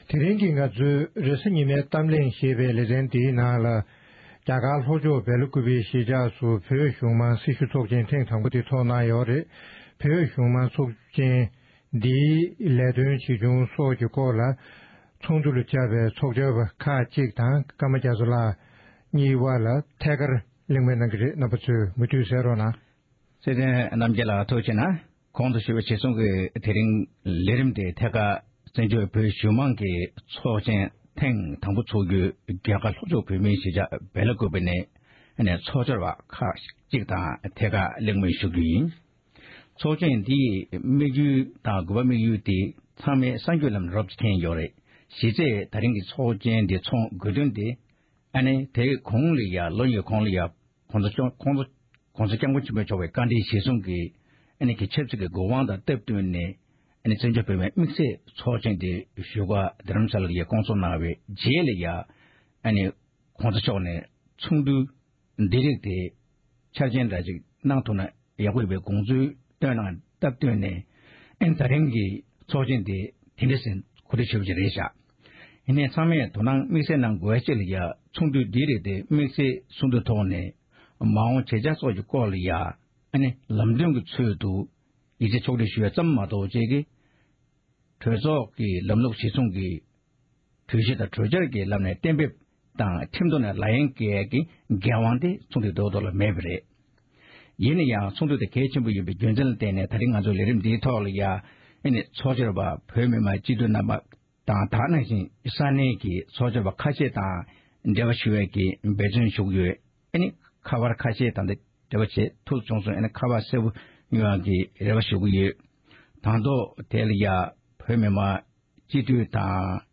༄༅༎དེ་རིང་གི་རེས་གཟའ་ཉི་མའི་གཏམ་གླེང་གི་ལེ་ཚན་ནང་།